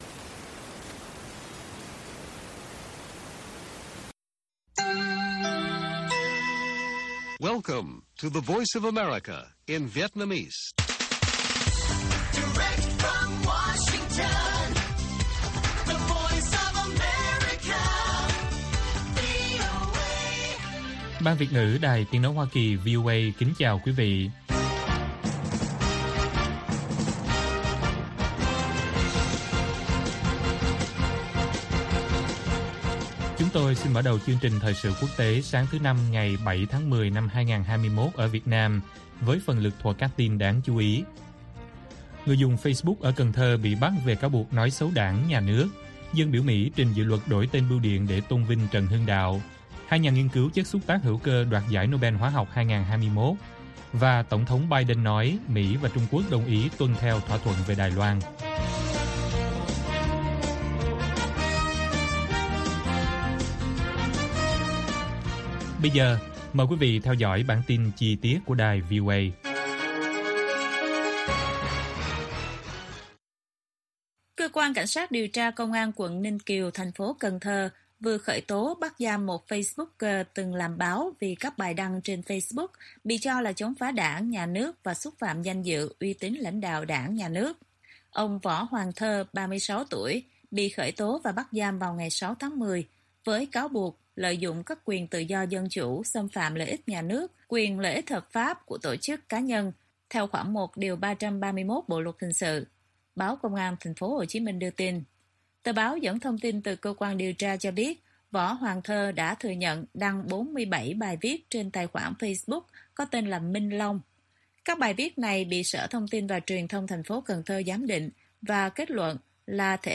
Bản tin VOA ngày 7/10/2021